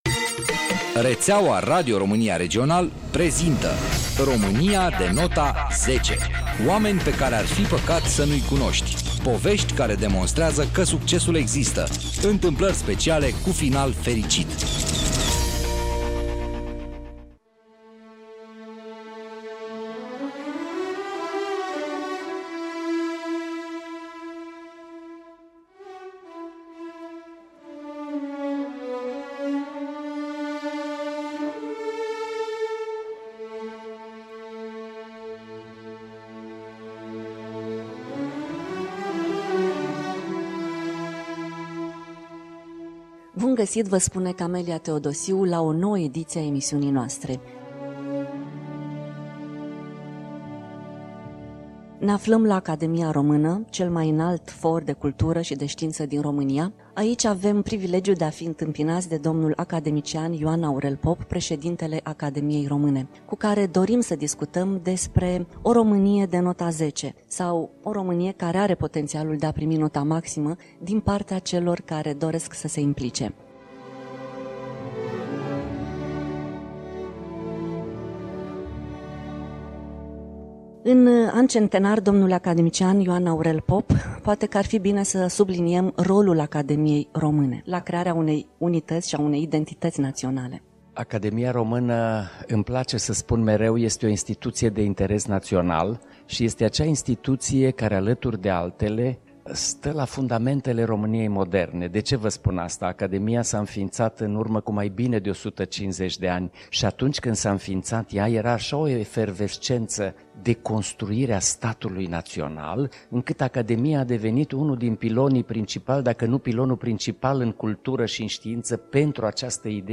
Împreună cu invitatul nostru, dl. academician Ioan Aurel Pop – Preşedintele Academiei Române, ne propunem să proiectăm o Românie care are potenţialul de a primi nota maximă, din partea celor care vor să se implice.